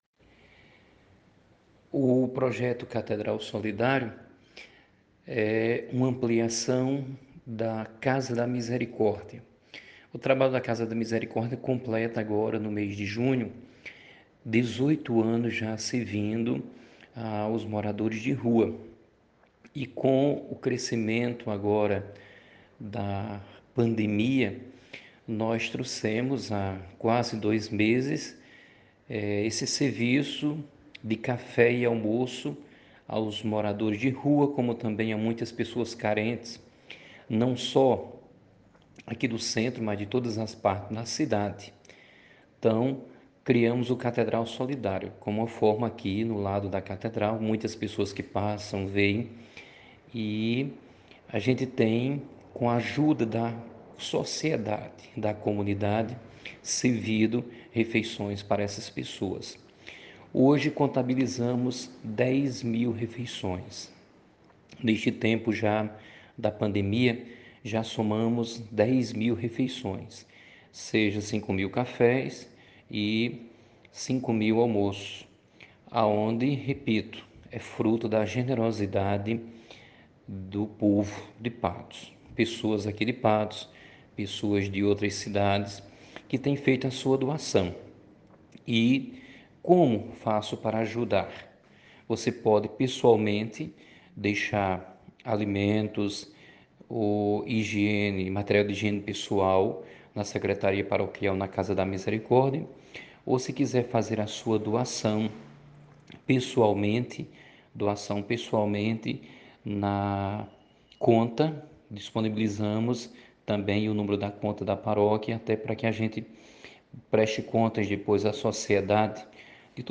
explicou na entrevista sobre o andamento deste projeto.